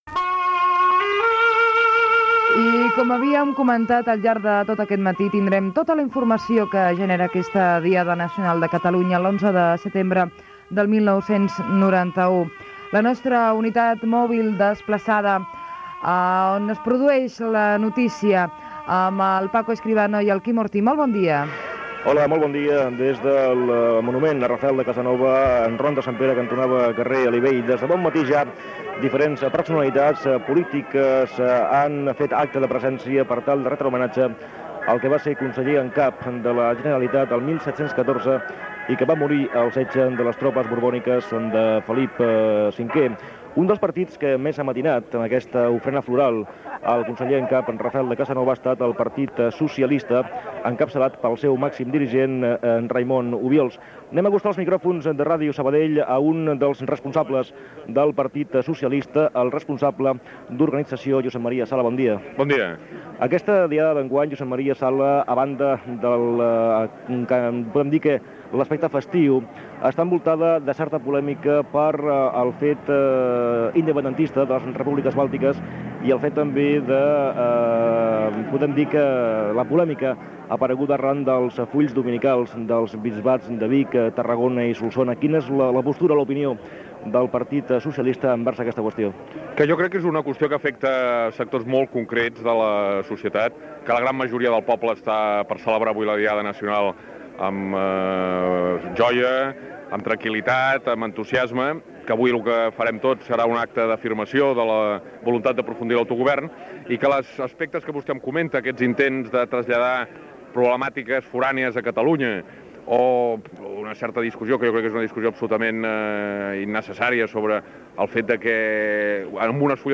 Connexió amb el monument de Rafel de Casanova, a Barcelona. Entrevistes a Josep Maria Sala del Partit Socialista de Catalunya i a Macià Alavedra de Convergència i Unió.
Informatiu